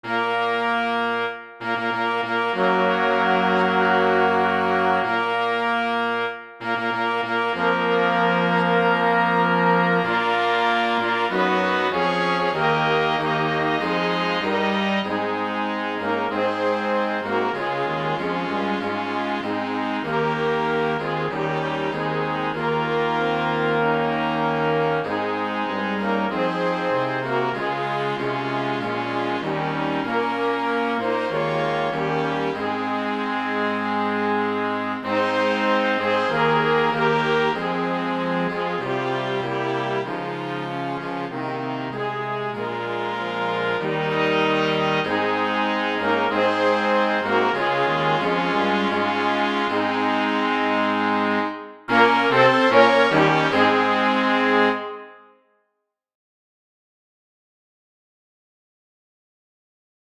This is just the wind parts.
CHORALE MUSIC